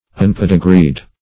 Unpedigreed \Un*ped"i*greed\, a. Not distinguished by a pedigree.
unpedigreed.mp3